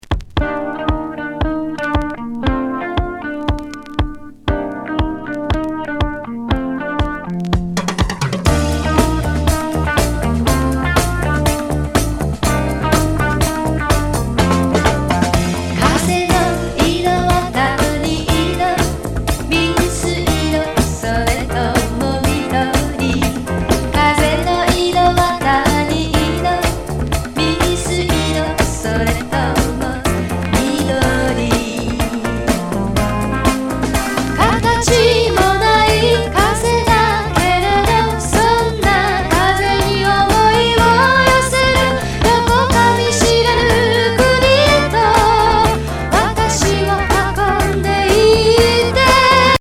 ハリウッド録音74年作。